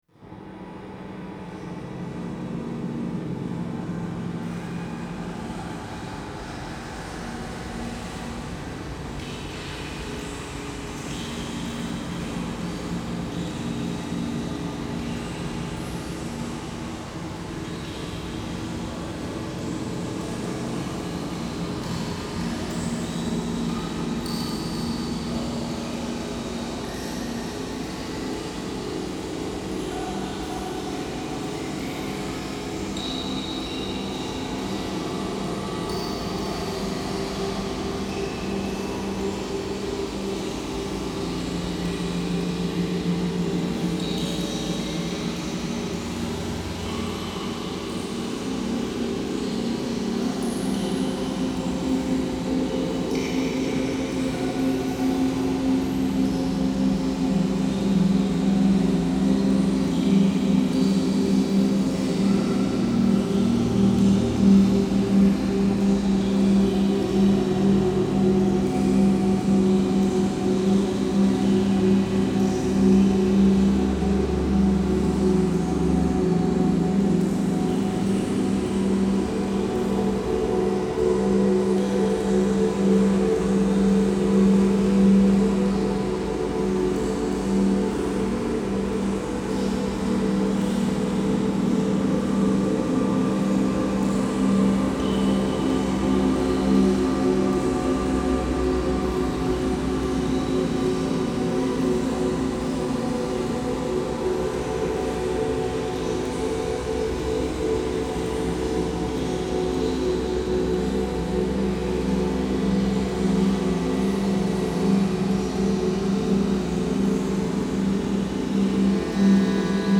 A recorded soundscape – any contextualized music experience – is accessible by a stratified idea of time.